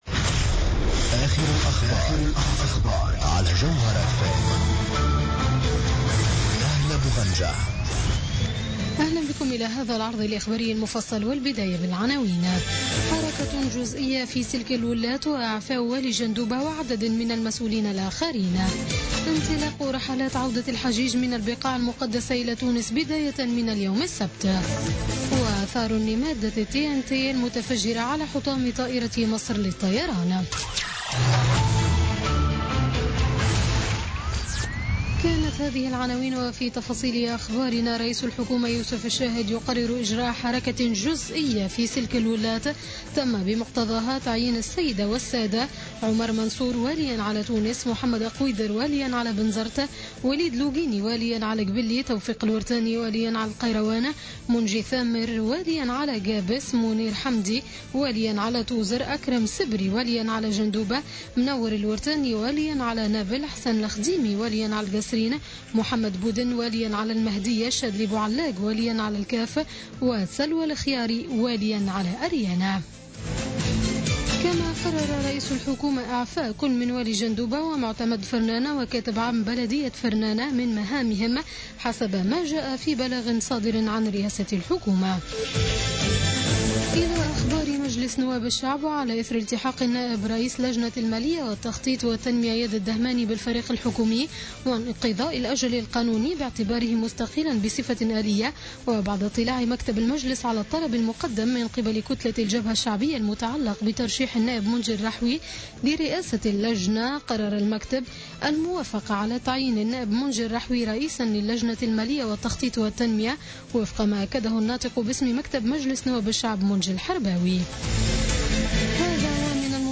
نشرة أخبار منتصف الليل ليوم السبت 17 سبتمبر 2016